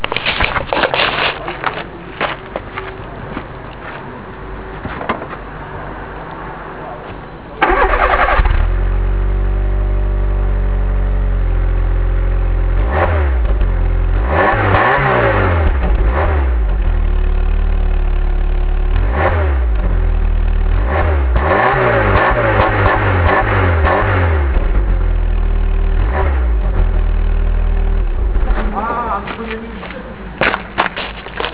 Nagrałem sobie mój wydech: